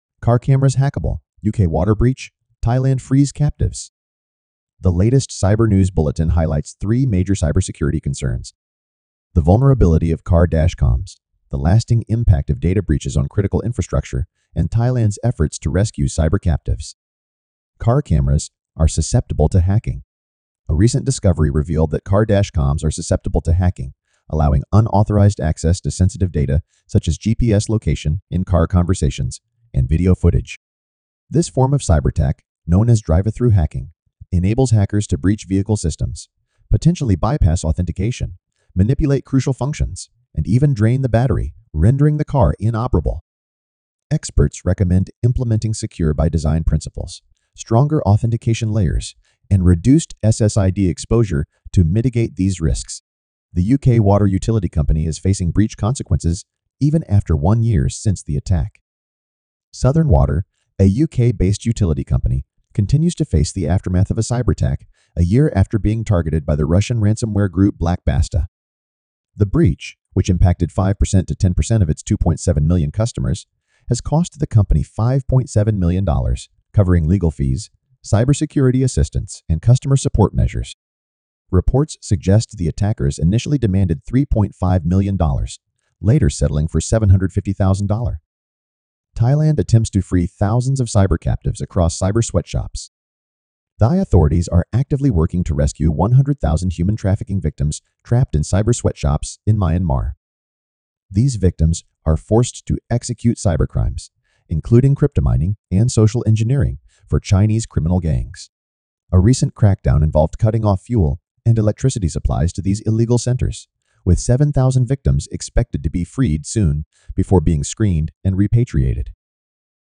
cyber news bulletin